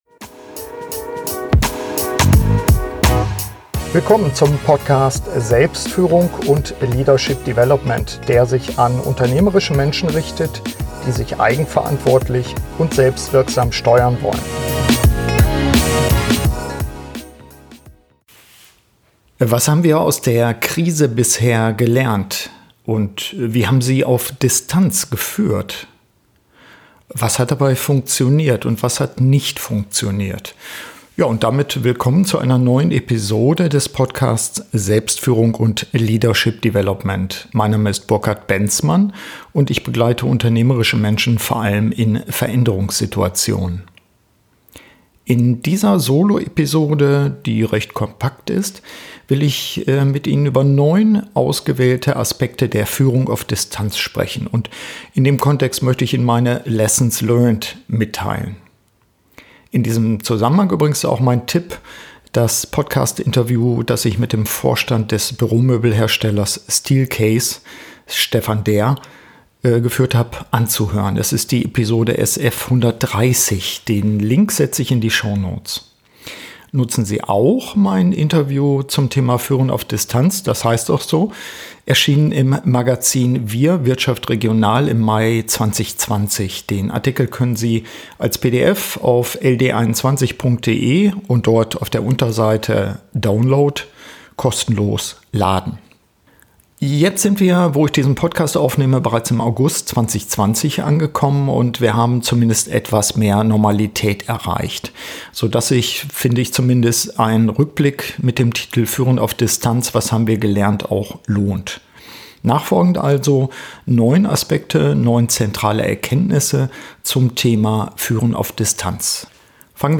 In dieser Soloepisode spreche ich über neun ausgewählte Aspekte der Führung auf Distanz und liefere Ihnen meine lessons learned.